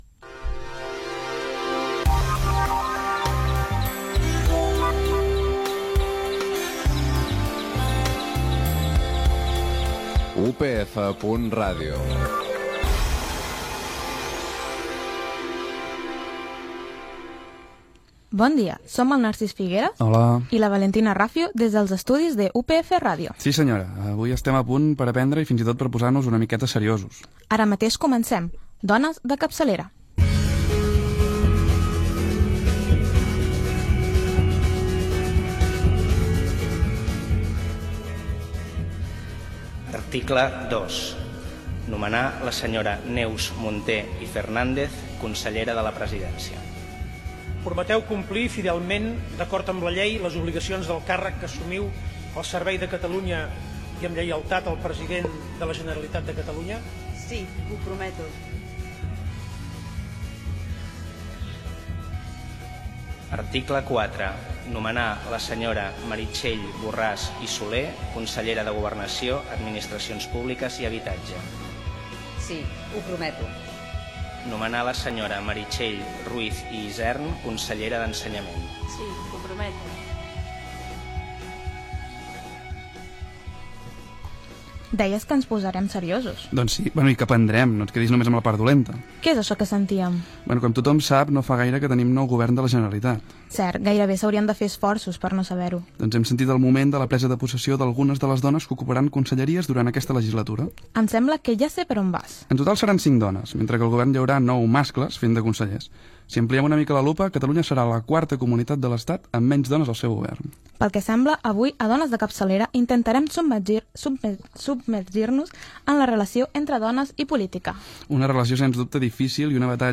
Indicatiu de l'emissora, presentació del programa, presa de posició de Conselleres del govern de la Generalitat, sumari de continguts, dones i política.
Divulgació